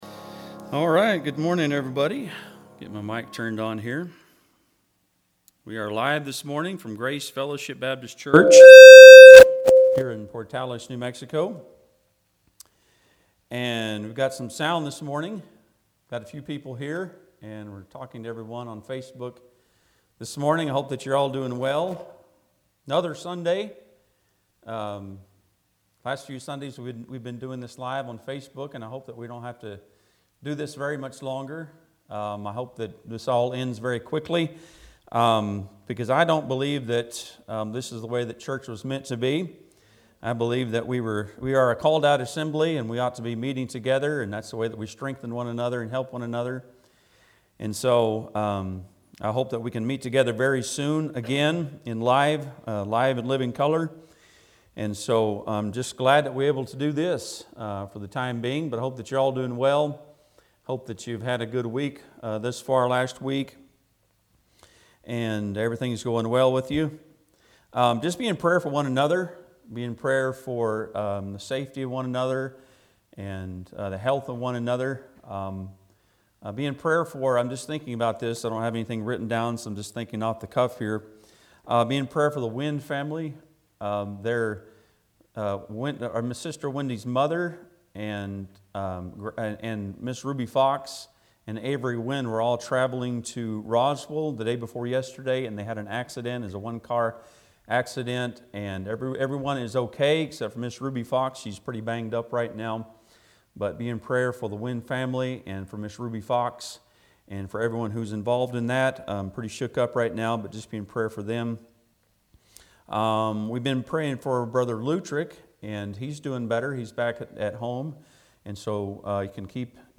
Judges 8:4 Service Type: Sunday am Bible Text